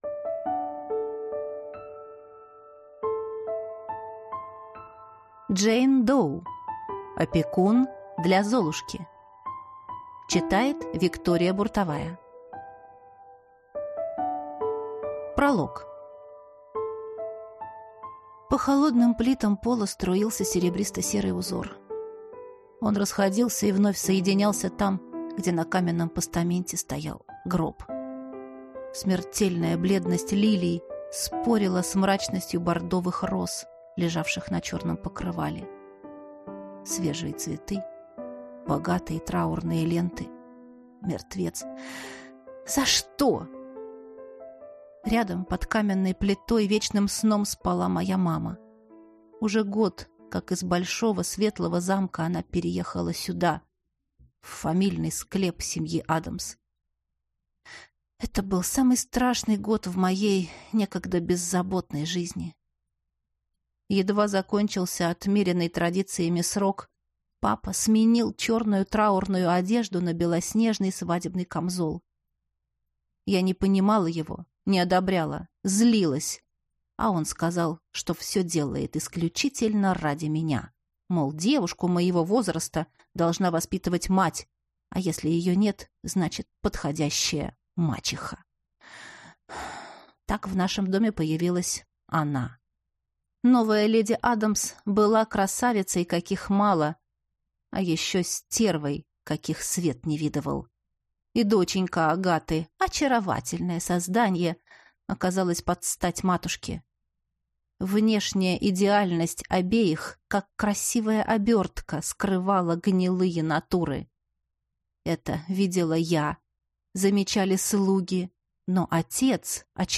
Аудиокнига Опекун для Золушки | Библиотека аудиокниг